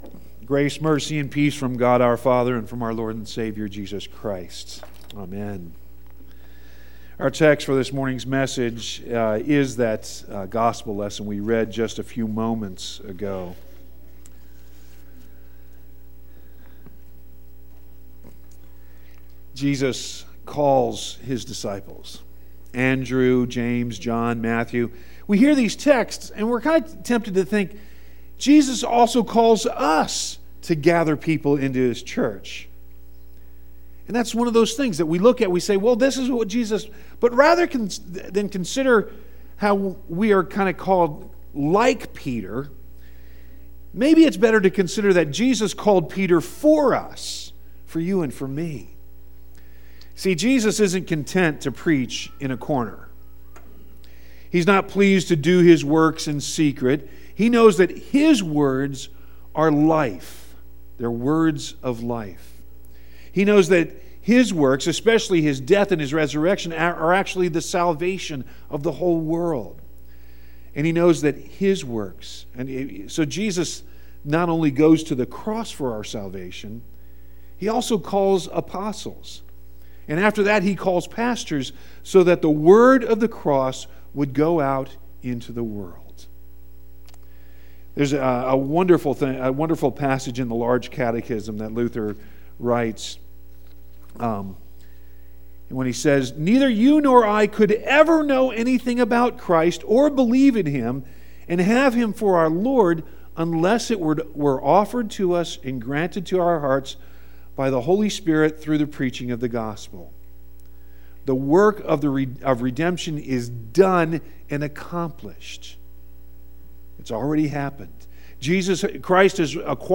You can also download the sermon directly HERE, or get all the sermons on your phone by subscribing to our Podcast HERE.